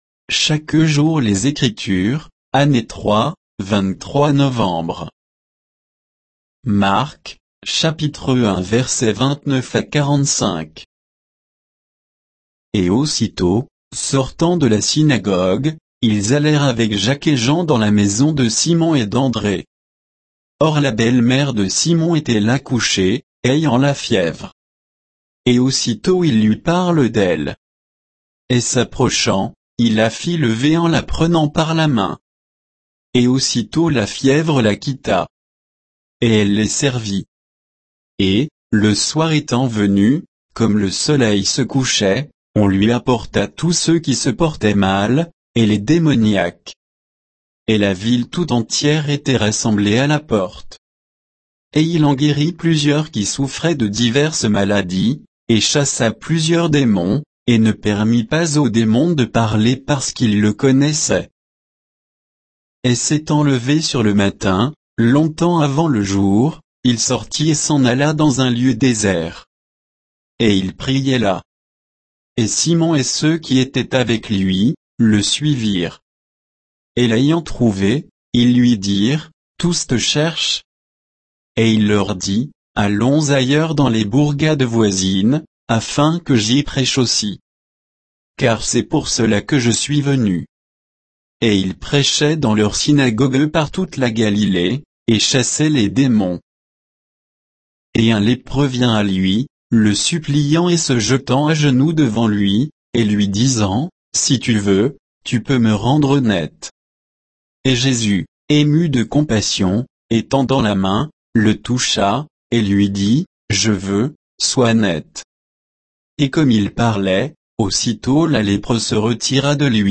Méditation quoditienne de Chaque jour les Écritures sur Marc 1